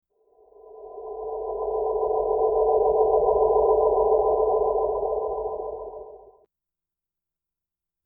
Teleportation_04.mp3